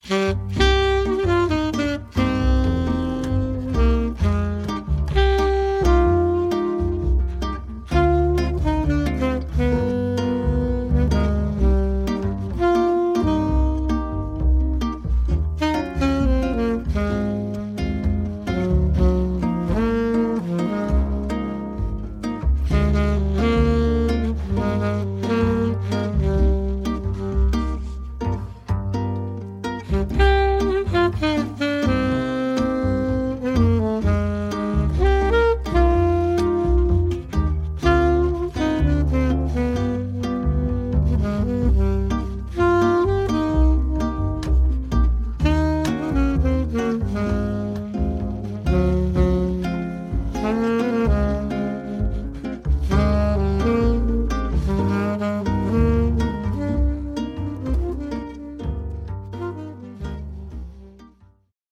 Instrumental Rat-Pack Jazz Band